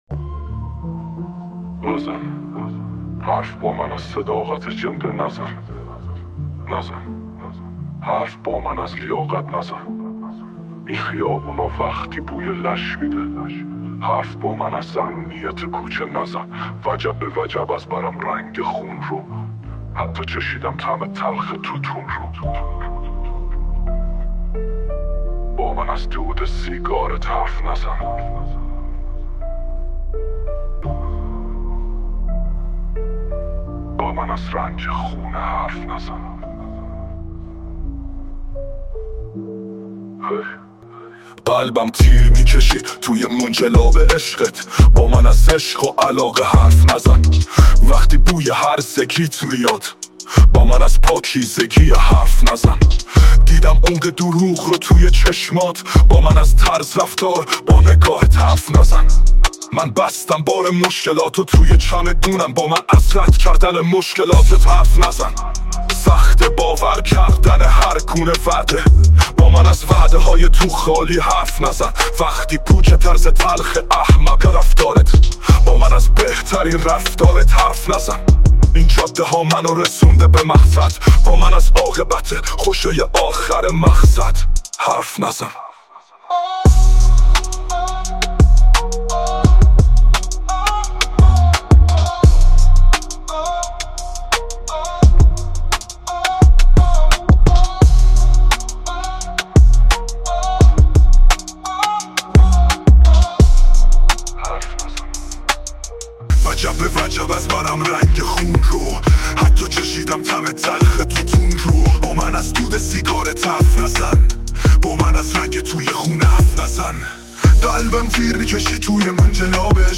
آهنگ شاد